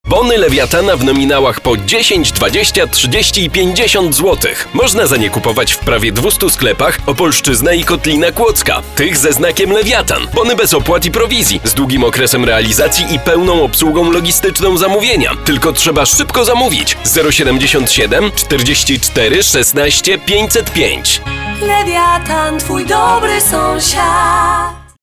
Professioneller polnischer Sprecher für TV / Rundfunk/Industrie.
Kein Dialekt
Sprechprobe: Industrie (Muttersprache):